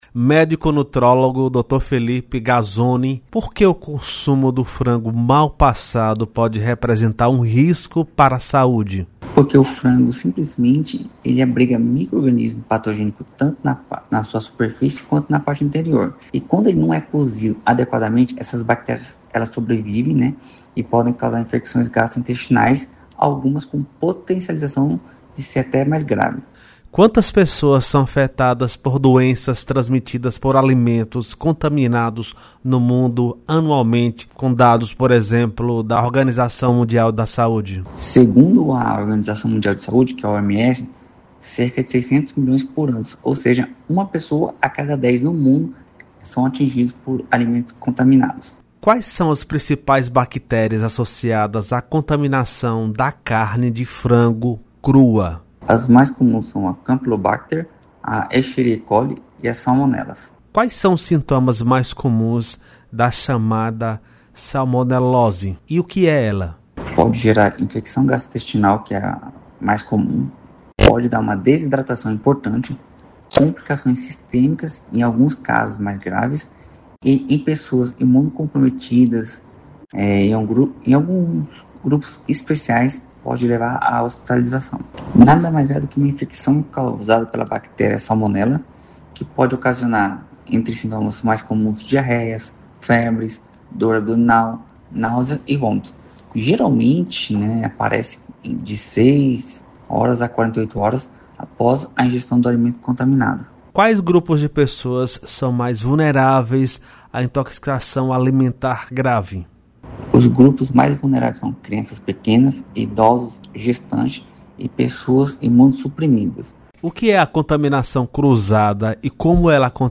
Médico nutrólogo